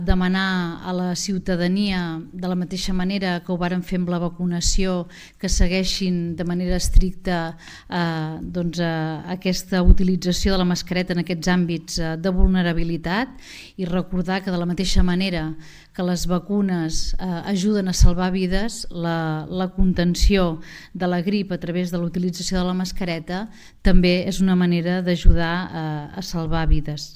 La portaveu del Govern, Sílvia Paneque, ha demanat la col·laboració de tothom per reduir la transmissió del virus i evitar la saturació dels serveis sanitaris: